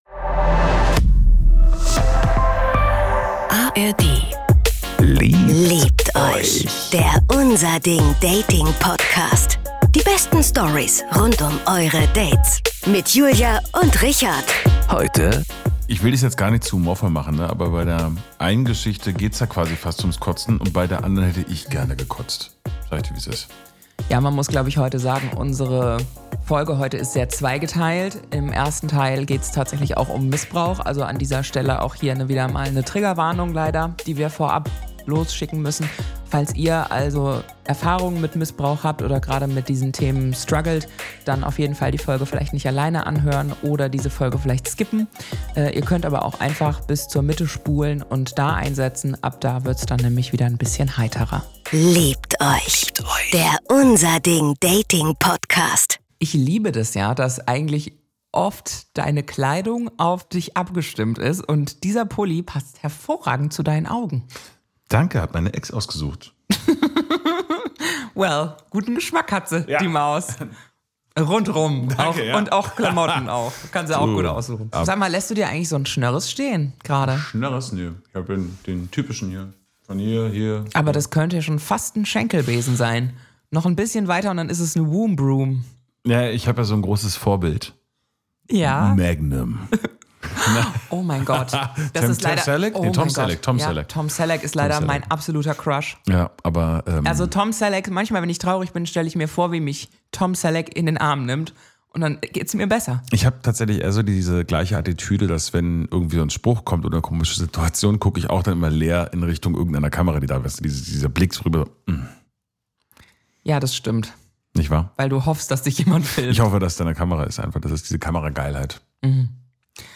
Eine junge Frau erzählt von einem Erlebnis mit ihrem Ex-Freund, bei dem sie keine Möglichkeit hatte, in eine sexuelle Handlung einzuwilligen.